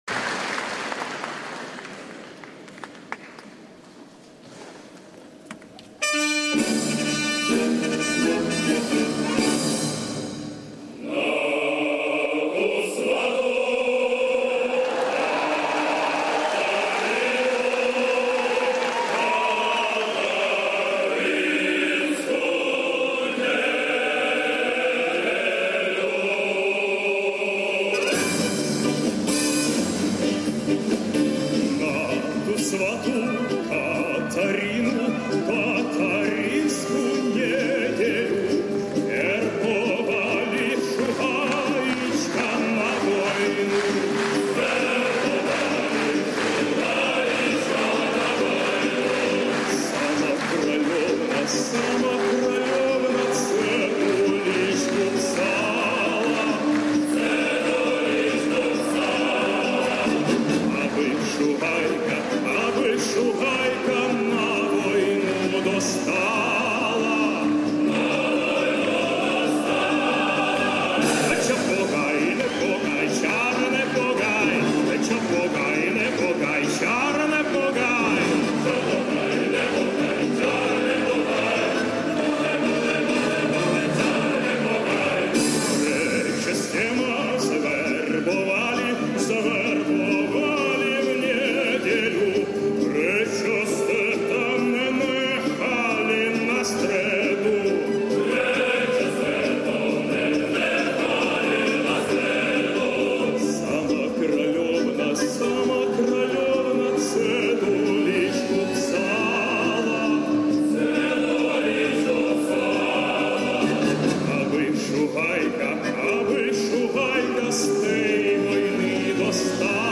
Концертная запись.
чешская народная песня Слова